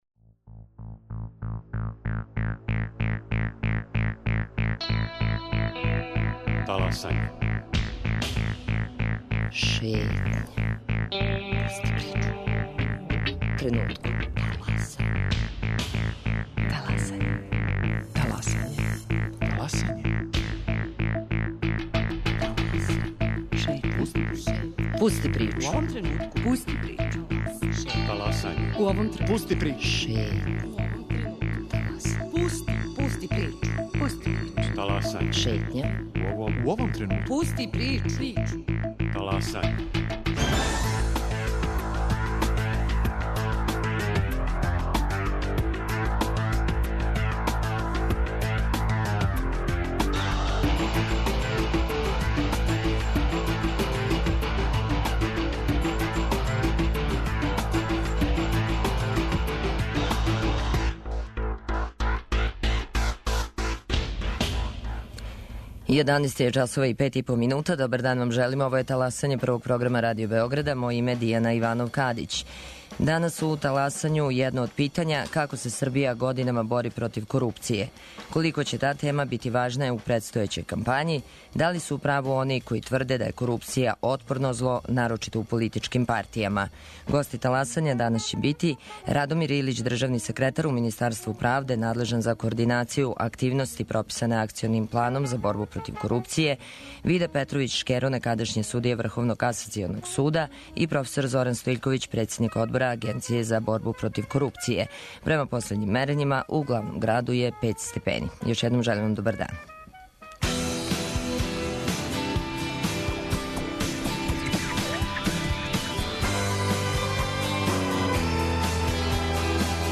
Гости Таласања: Радомир Илић-државни секретар у Министарству правде, Вида Петровић Шкеро-некадашњи судија Врховног касационог суда и Зоран Стојиљковић-председник одбора Агенције за борбу против корупције.